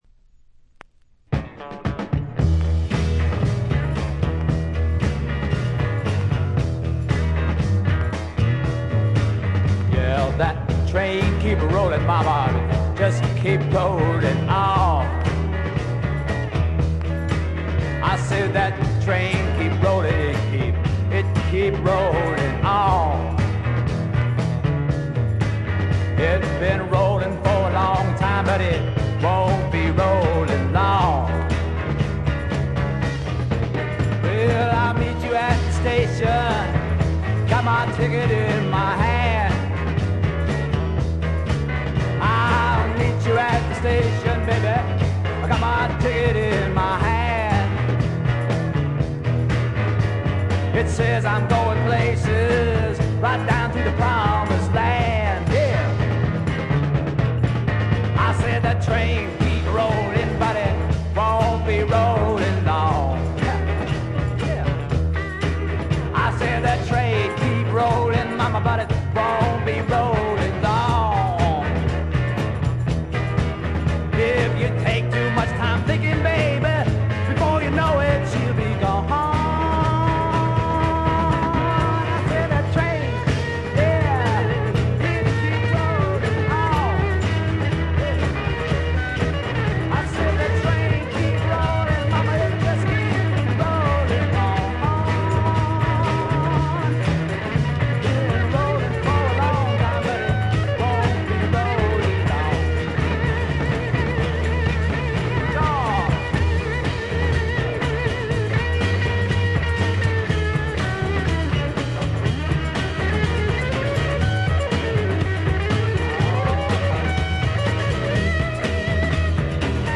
静音部での微細なチリプチ程度。
試聴曲は現品からの取り込み音源です。